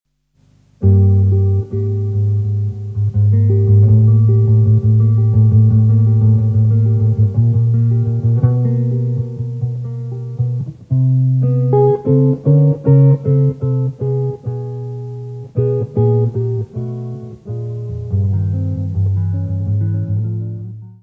guitarra
saxofone alto e saxofone soprano
trombone
bateria
contrabaixo.
Área:  Jazz / Blues